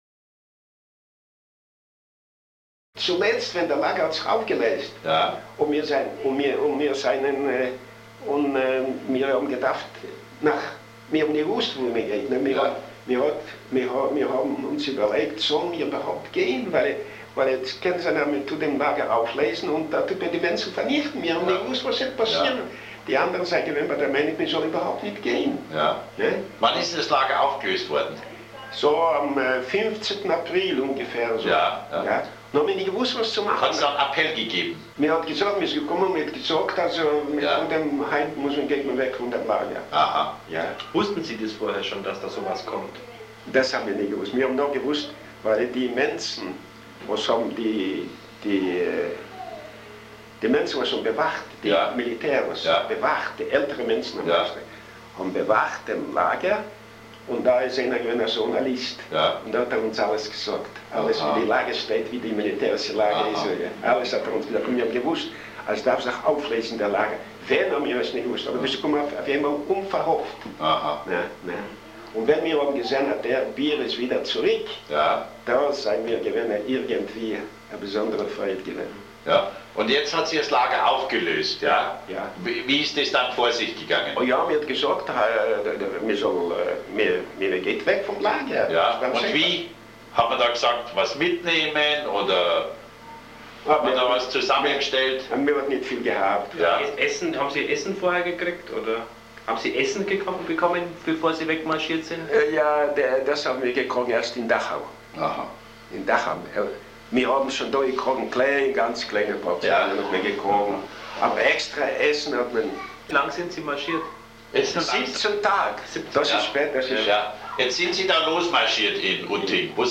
Auszug aus einem Interview